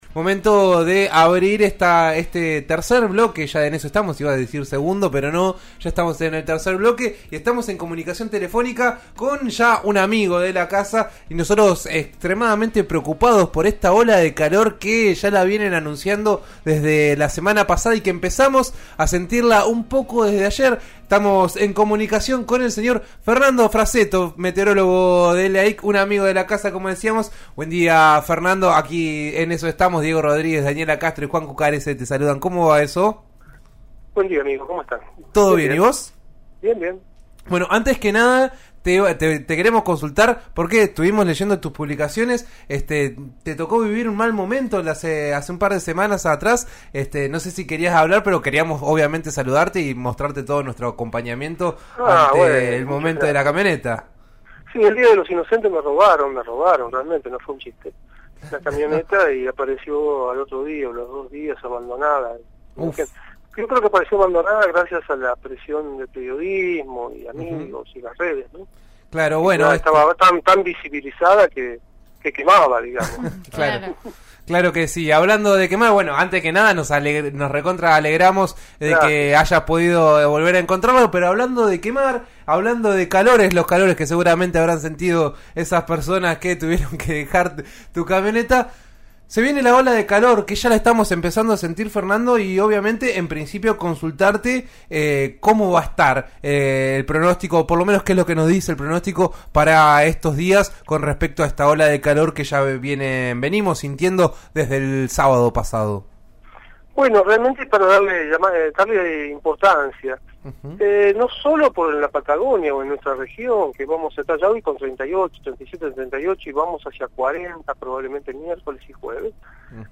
El meteorólogo contó cuáles son las condiciones que acompañan este contexto veraniego, al aire de 'En Eso Estamos' de RN RADIO.